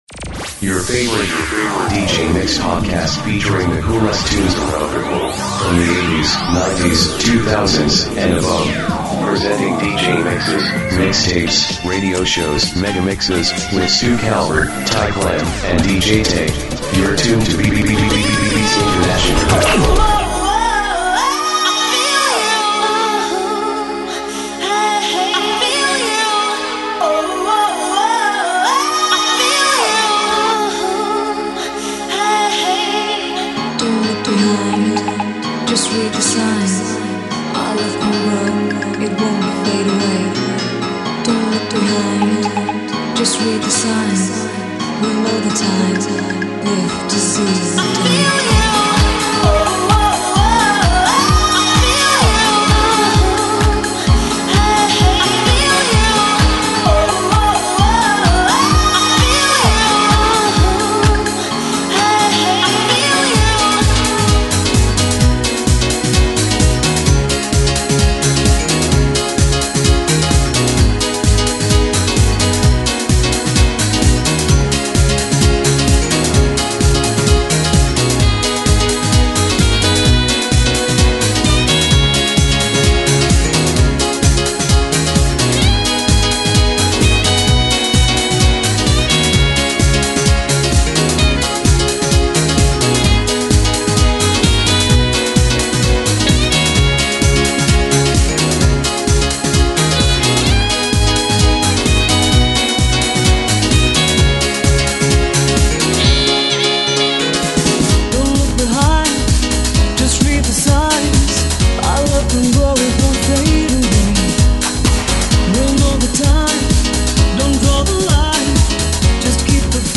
Part #6 of a 90’s Dance Mix series.